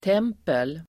Uttal: [t'em:pel]